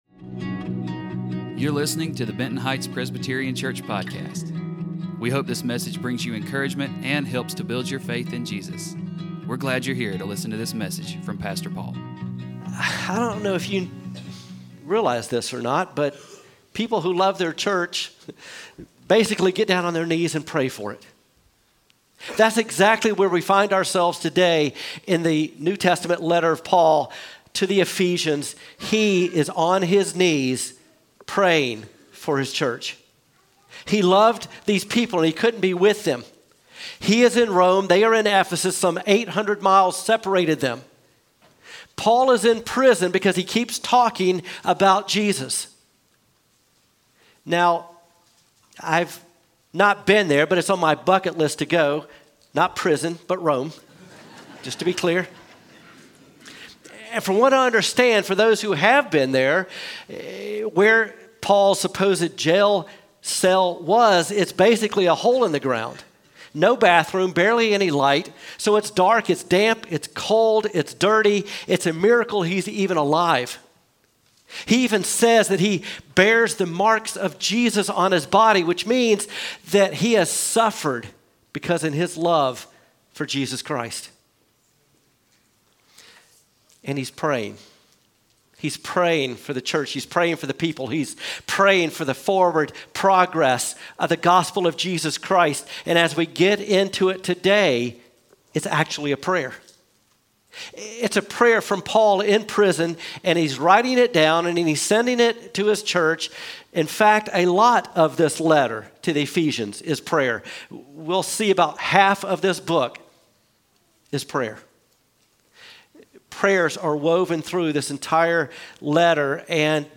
Weekly sermons from Benton Heights Presbyterian Church in Monroe, NC.